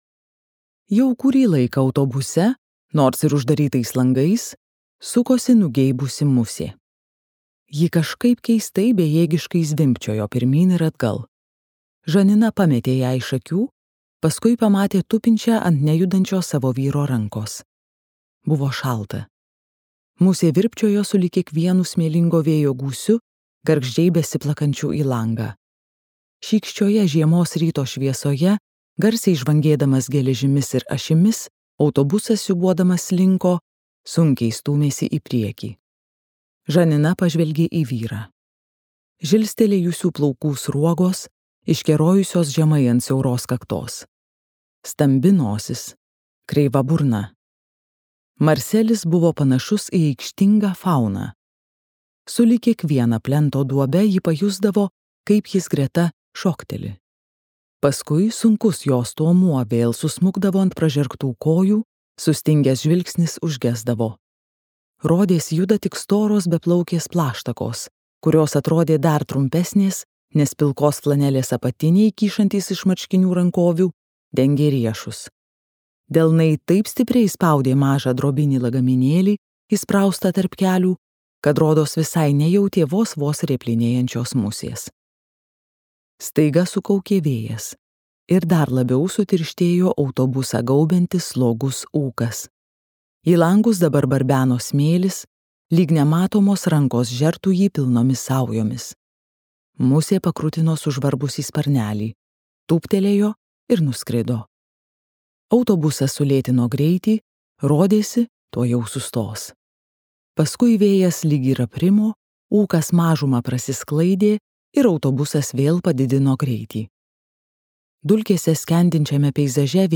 Tremtis ir karalystė | Audioknygos | baltos lankos